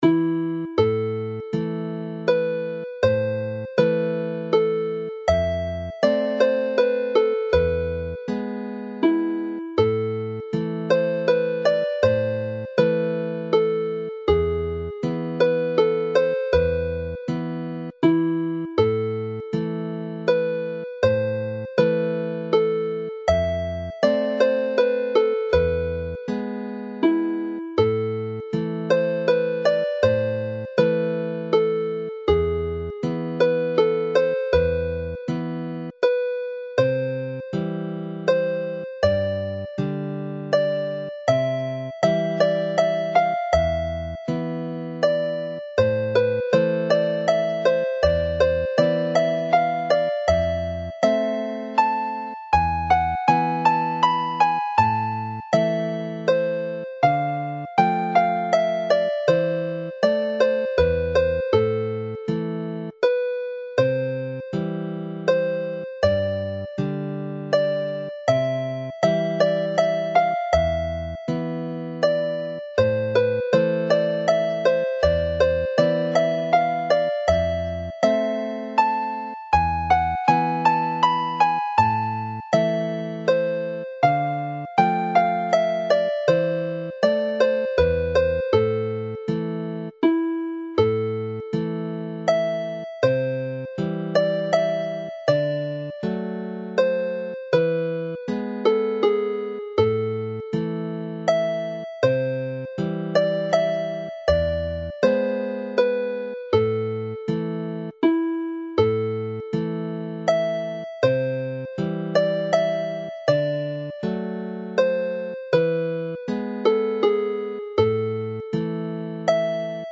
Alawon Cymreig - Set Blodau'r Drain- Welsh folk tunes to play
All three melodies are in the Welsh A minor which use a G# in the scale, giving an E major chord rather than the E minor generally found in Irish and Scottish tunes.